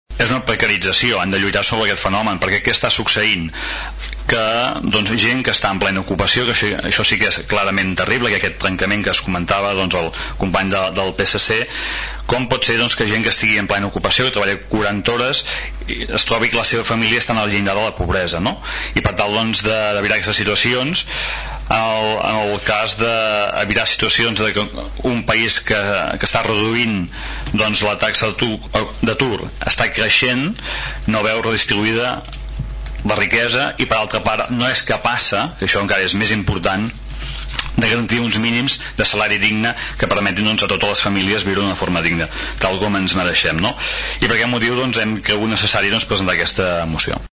El ple de l’Ajuntament de Tordera aprova una moció al voltant de les empreses multiserveis
Des d’ERC+Gent de Tordera, el regidor Xavier Pla explicava que han promogut aquesta moció perquè, davant l’actual descens de l’atur, cal anar més enllà i lluitar contra els contractes temporals i els salaris baixos, els quals reverteixen negativament en la recuperació de l’economia catalana.